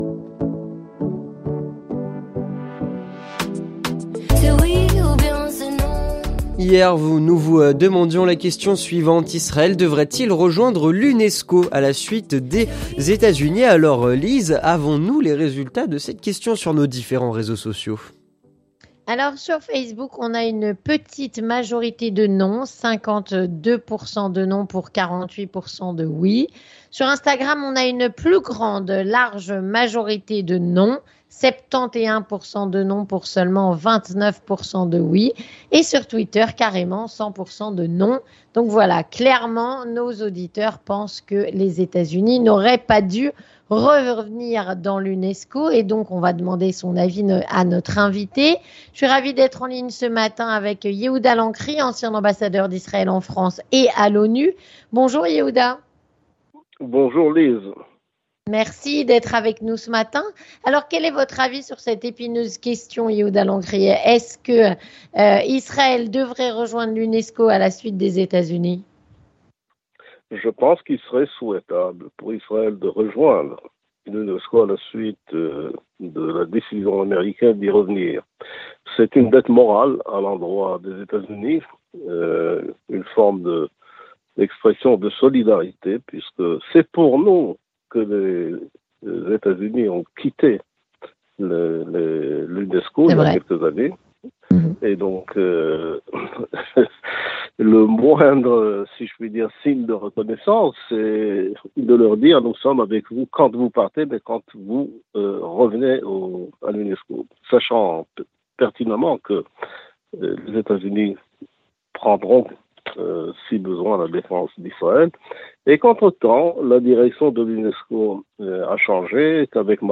Avec Yehuda Lancry ancien ambassadeur d’Israël en France et à l’ONU